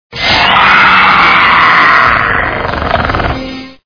Sfx: Velociraptor roaring.
raptor.wav